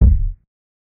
Kicks
KICK_CHECK_THE_CREDITS.wav